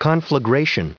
161_conflagration.ogg